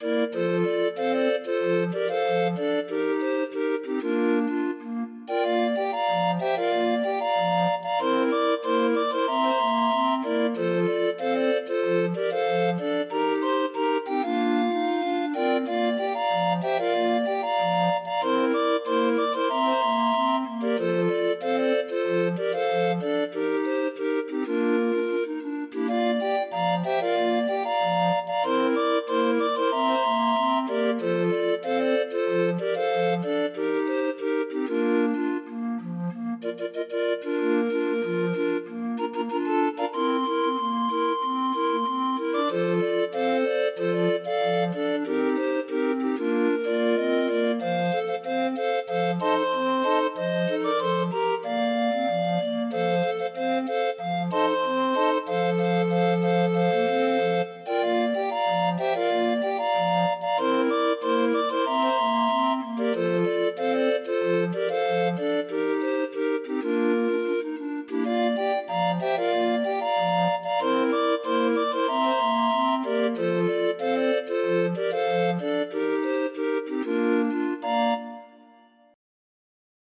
Demo of 20 note MIDI file